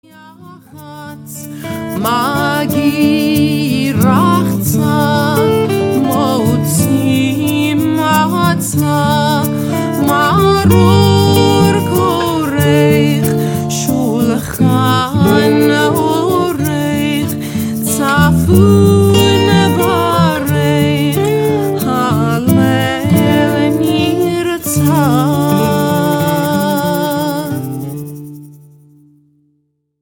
Oriental